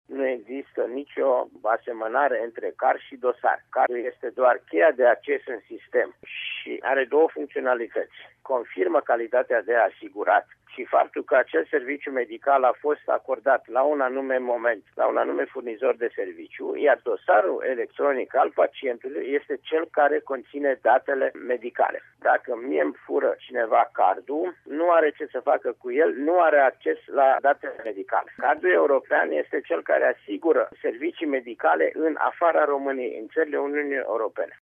Ce trebuie să ştie posesorii acestuia despre el şi cum să nu-l confunde cu cardul european sau cu dosarul electronic- cel care conţine datele medicale – aflăm de la preşedintele CNAS, Vasile Ciurchea: